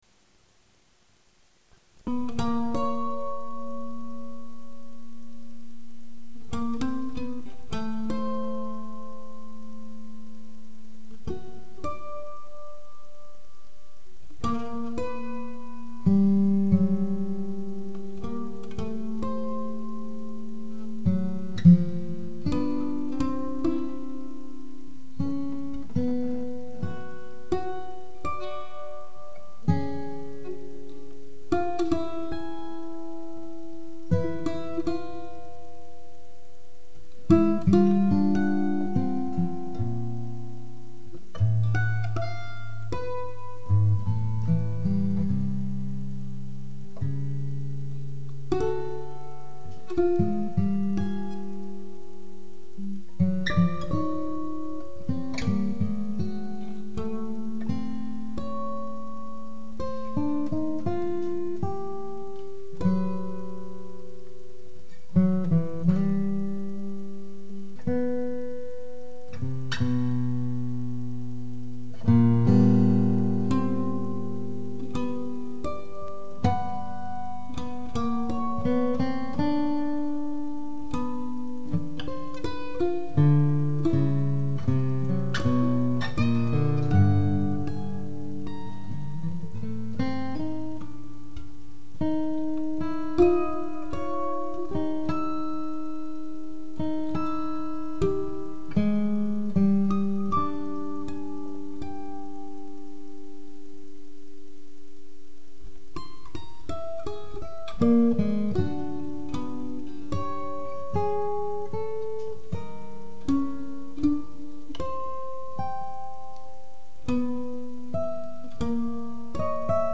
Duet for Mandolin and Guitar